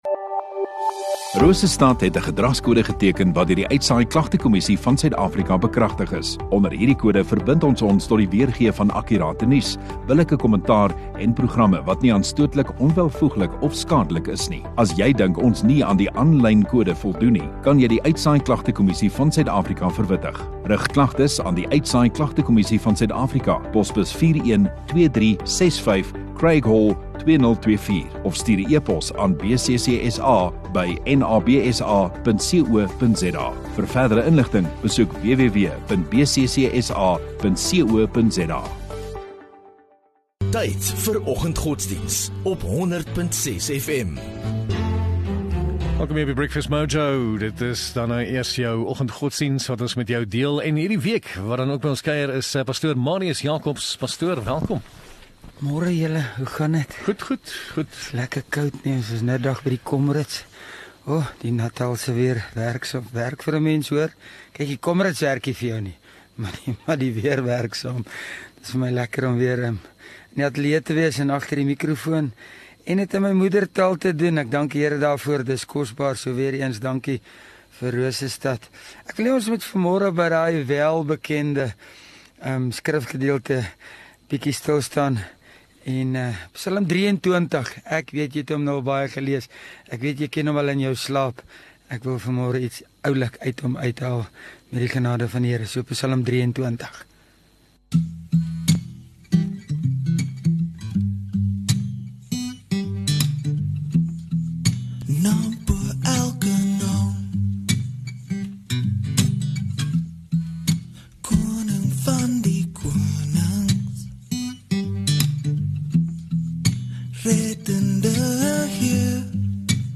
1 Jul Maandag Oggenddiens